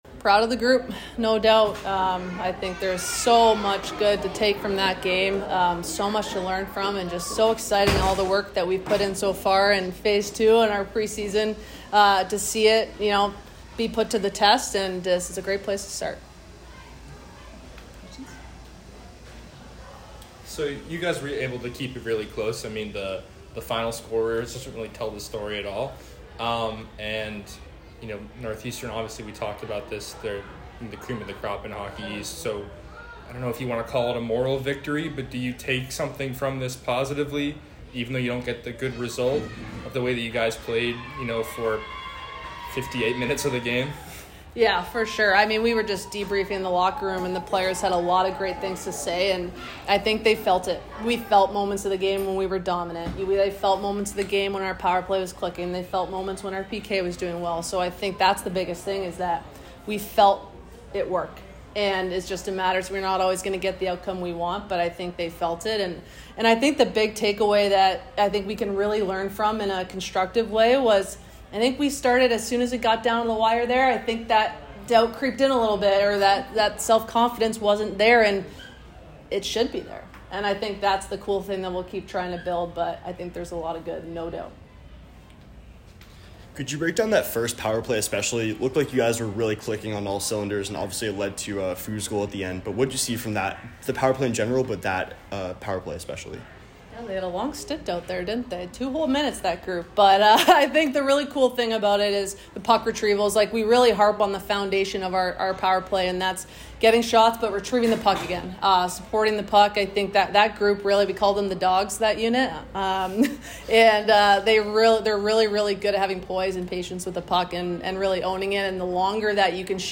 Women's Ice Hockey / Northeastern Postgame Interview